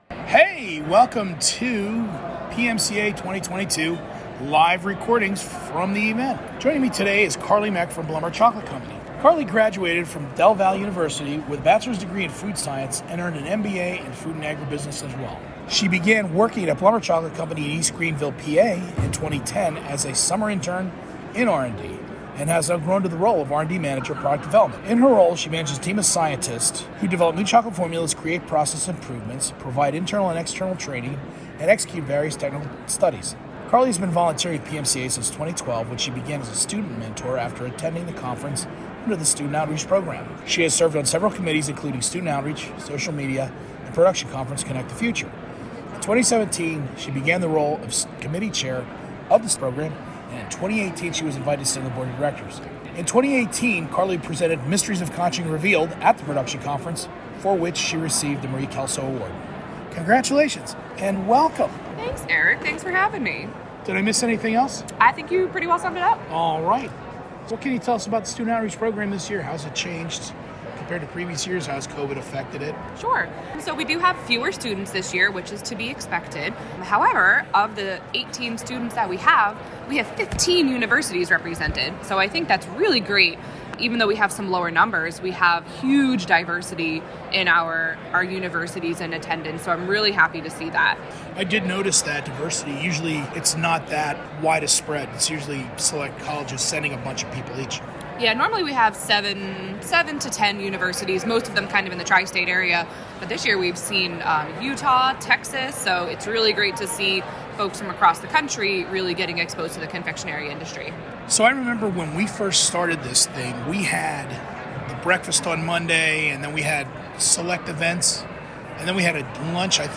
Episode 19: Live from PMCA 2022 - Committee Crossover